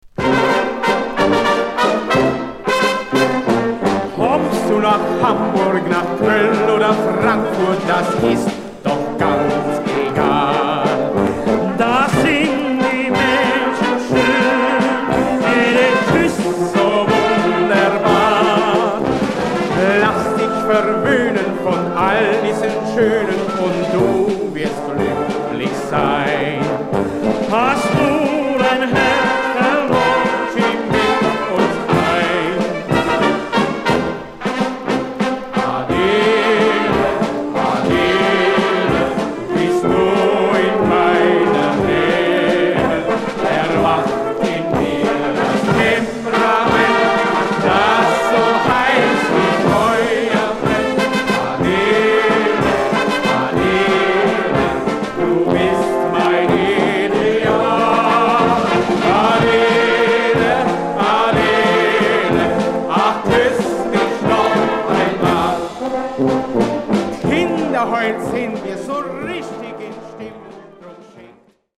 Schallplattenaufnahme